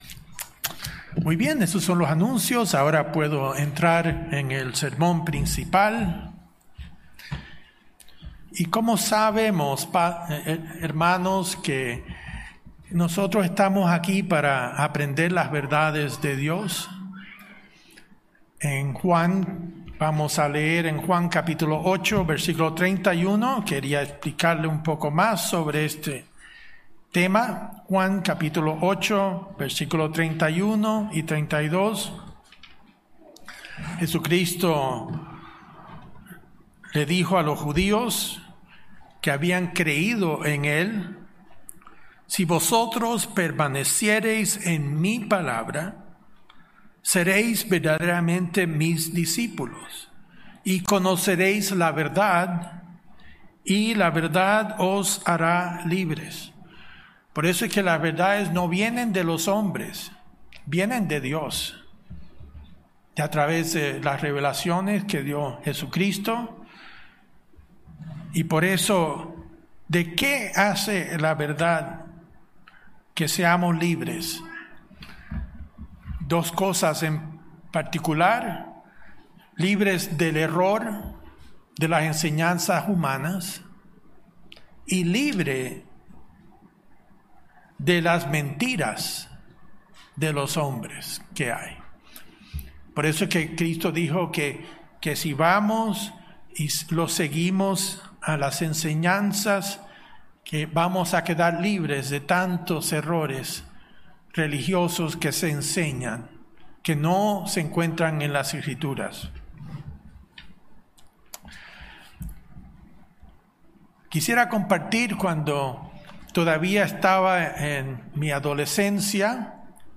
Sermones
Given in Santiago